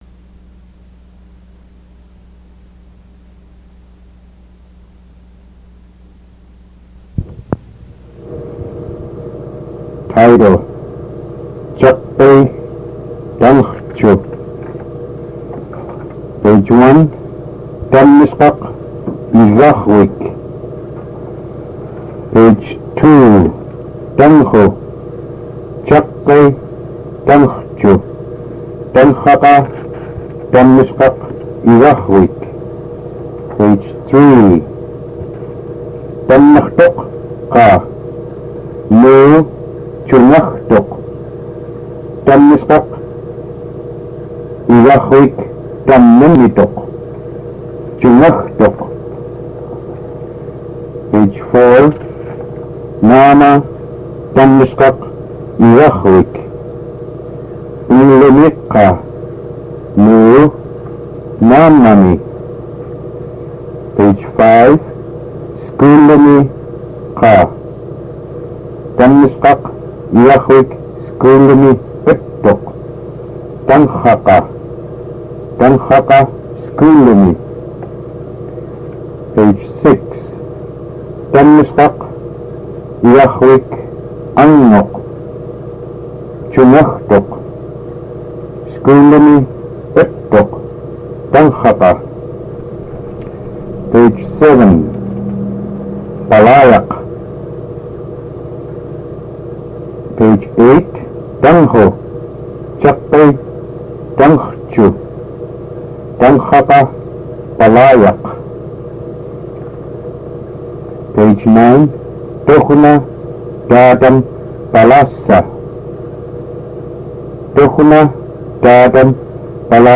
Caqai tangrrcu?, reading from a book Location: Location Description: Kodiak, Alaska